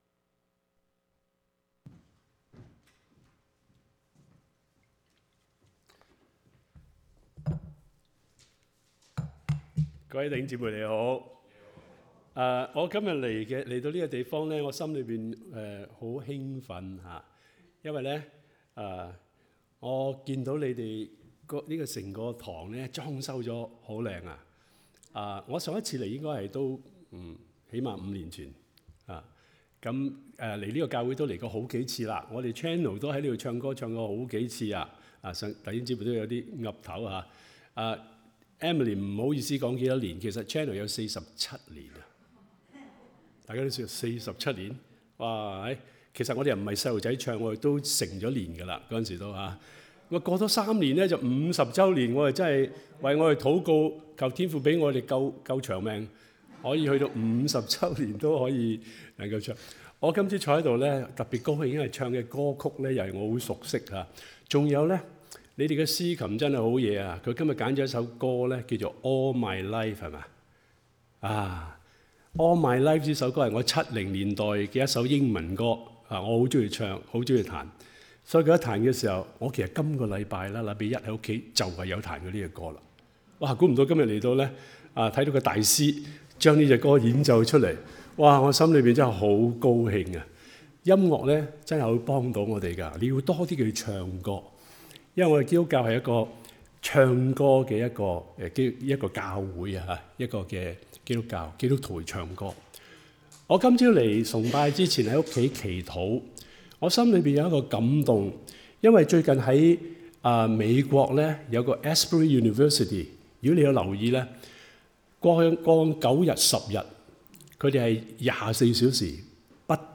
Sermons | New Covenant Alliance Church (NCAC) 基約宣道會 - Part 12